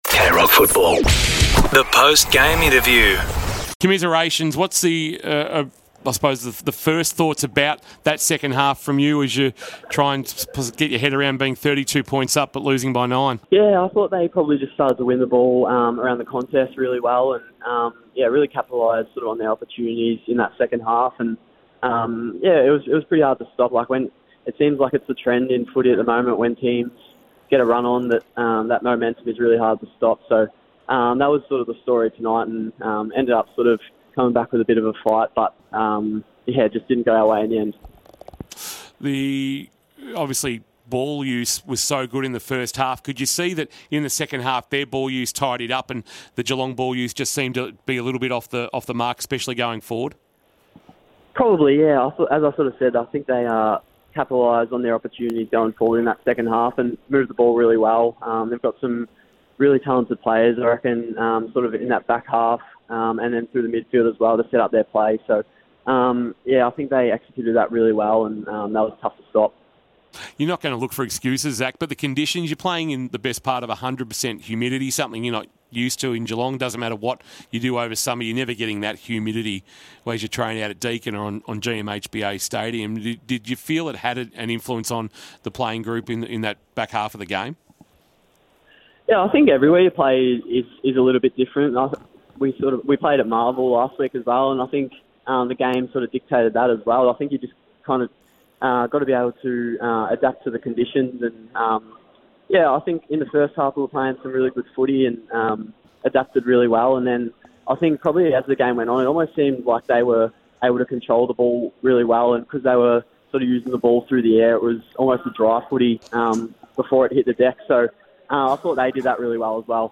2025 - AFL Round 3 - Brisbane Lions vs. Geelong: Post-match interview - Zach Guthrie (Geelong Cats)